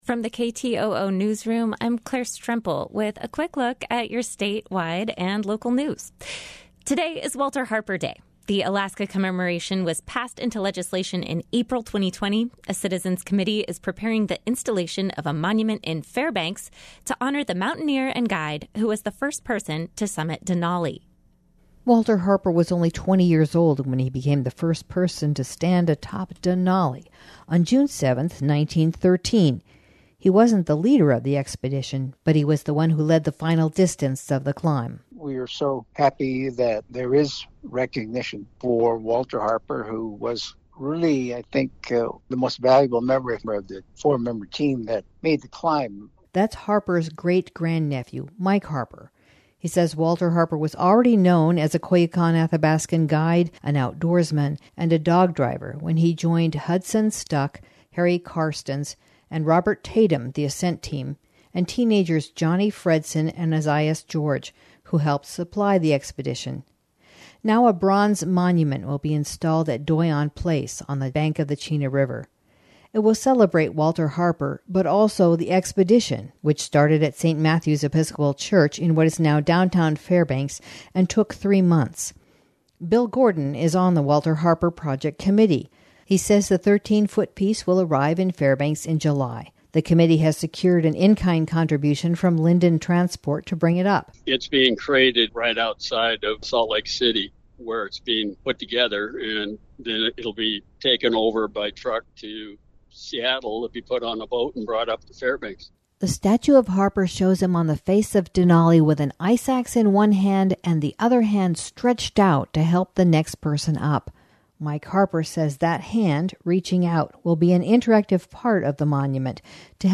Newscast — Tuesday, June 7, 2022